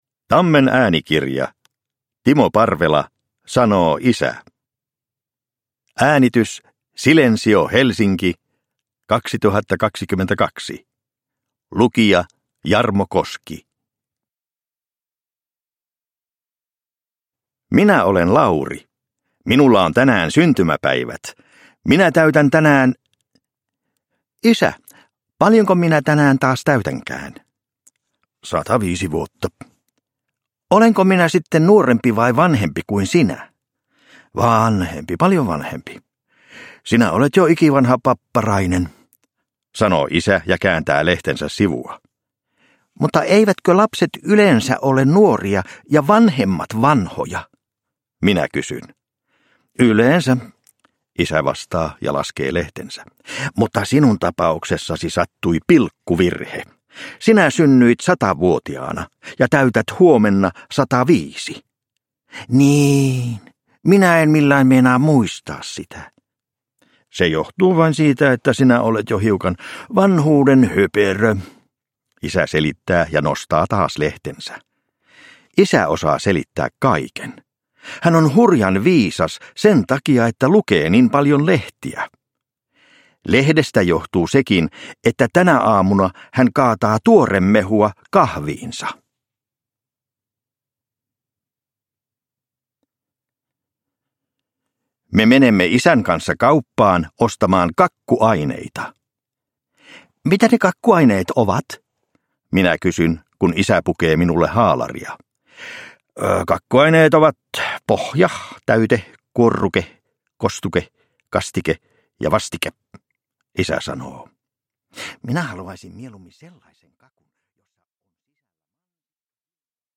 Sanoo isä – Ljudbok – Laddas ner